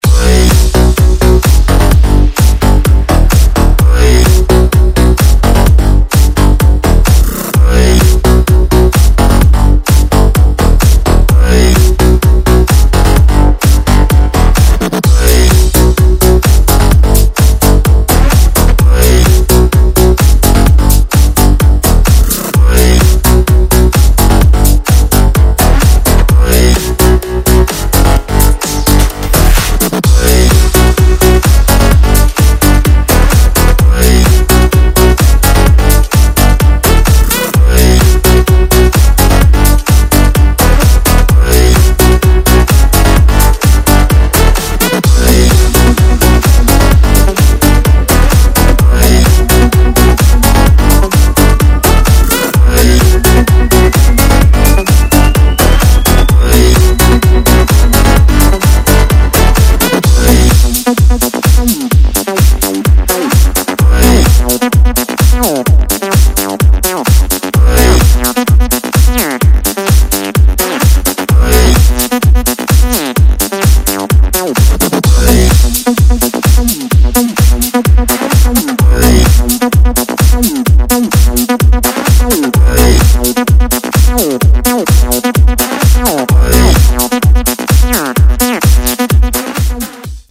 жанр этого трека ELECTRO HOUSE